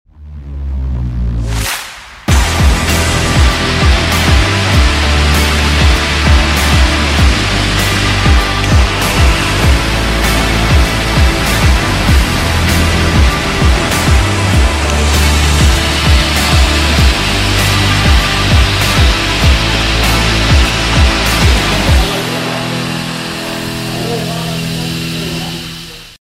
Part 3/3 This solid rocket sound effects free download
Part 3/3 This solid rocket sound effects free download By hyend_uni_stuttgart 0 Downloads 14 months ago 26 seconds hyend_uni_stuttgart Sound Effects About Part 3/3 This solid rocket Mp3 Sound Effect Part 3/3 This solid rocket motor was fired to evaluate a newly developed solid fuel at MPA University of Stuttgart. This is one of four tests performed in April. As it is only for fuel evaluation, it doesn't have a proper diverging section, therefore resulting in a short and stubby exhaust.